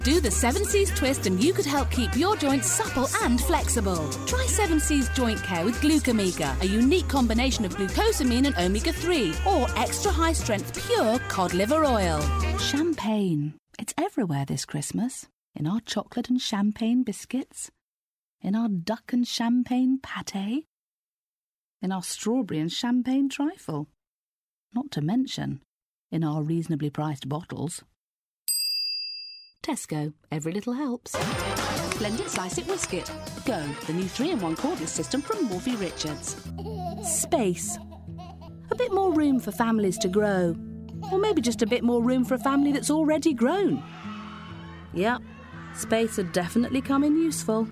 Commercial Showreel
Straight
Commercial Showreel, Distinctive